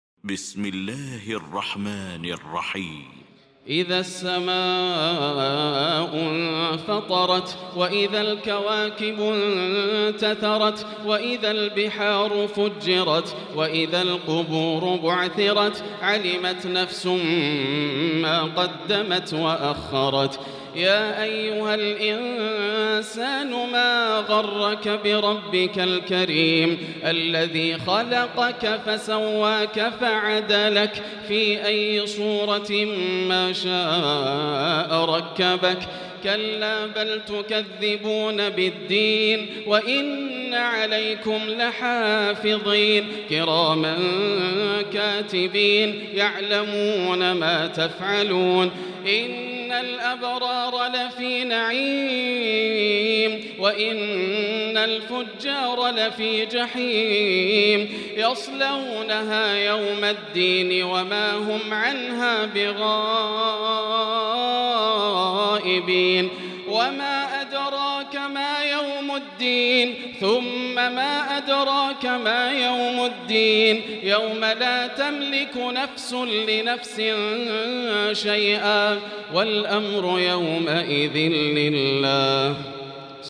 المكان: المسجد الحرام الشيخ: فضيلة الشيخ ياسر الدوسري فضيلة الشيخ ياسر الدوسري الانفطار